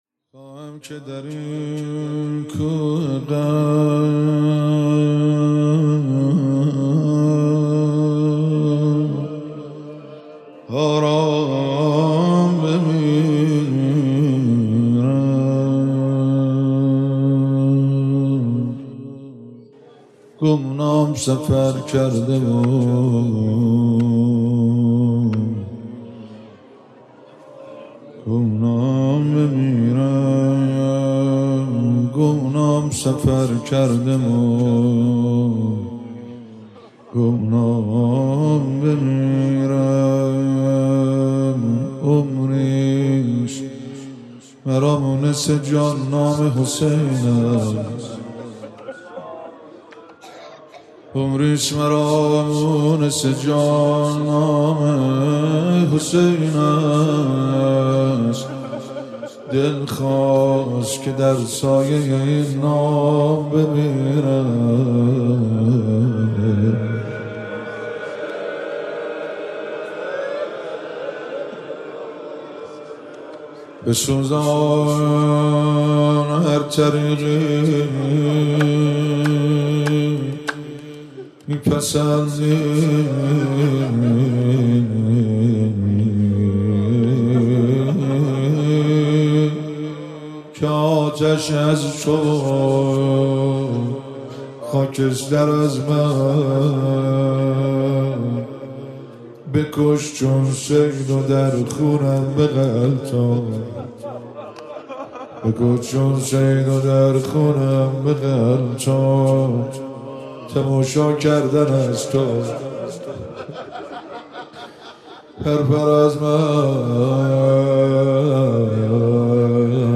مراسم هفتگی25آذر
روضه پایانی - خواهم که در این کوه غم آرام بمیرم